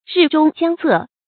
日中將昃 注音： ㄖㄧˋ ㄓㄨㄙ ㄐㄧㄤˋ ㄗㄜˋ 讀音讀法： 意思解釋： 比喻事物盛極將衰。